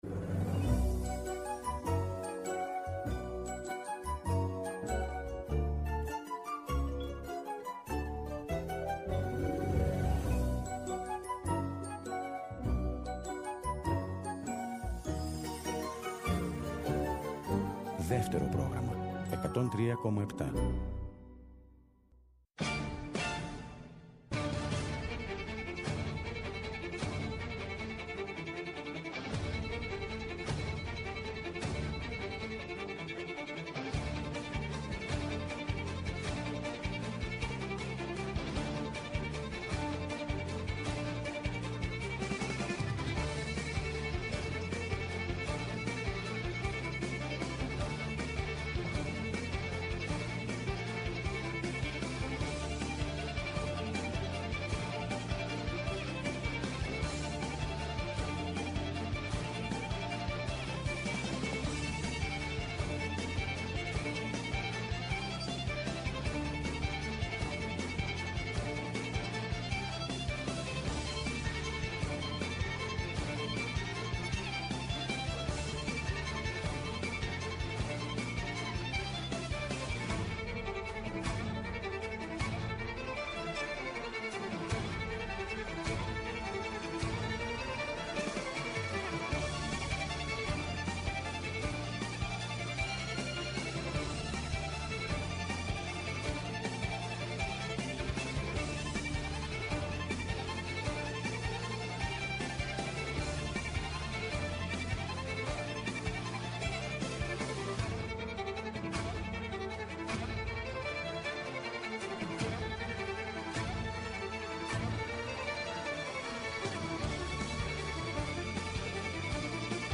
Παίζουν εμβληματικές ροκ διασκευές αλλά και δικά τους.